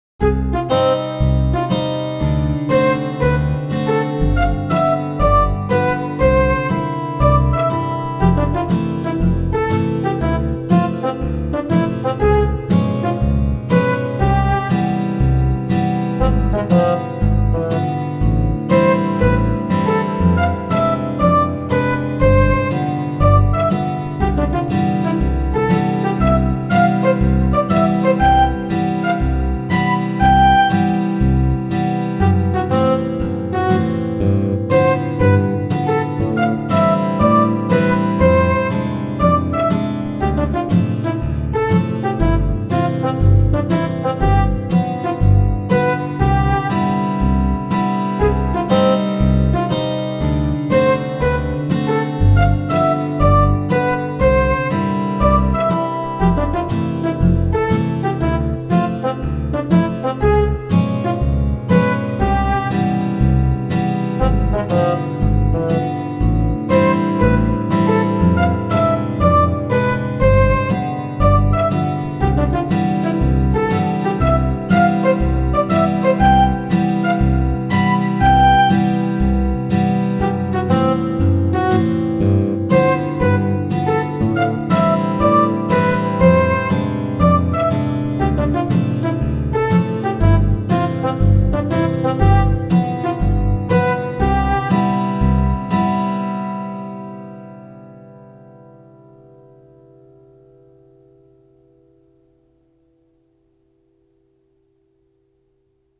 jazz march 15 2021